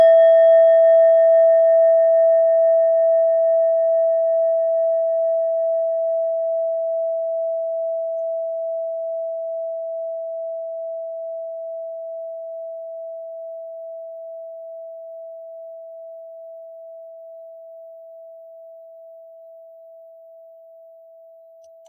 Klangschale Nepal Nr.21
Klangschale-Durchmesser: 11,8cm
(Ermittelt mit dem Filzklöppel oder Gummikernschlegel)
klangschale-nepal-21.wav